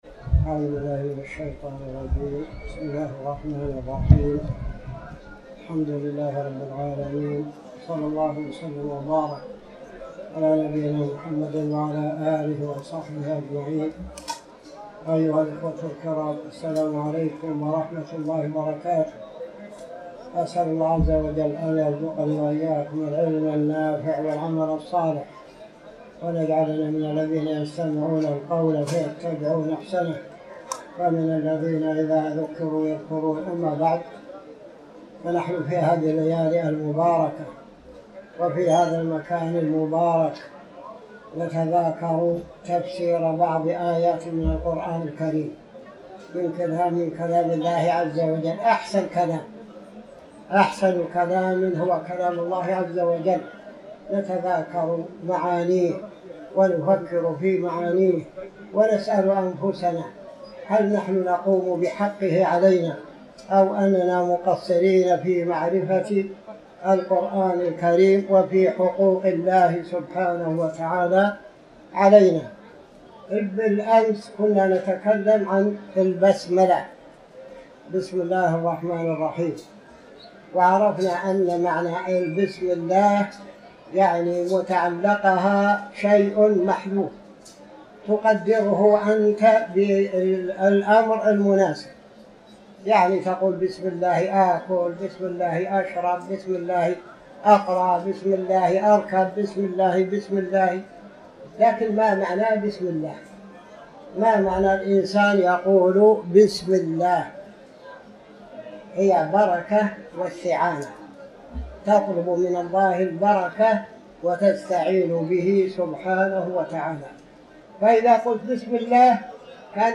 تاريخ النشر ٢١ رمضان ١٤٤٠ هـ المكان: المسجد الحرام الشيخ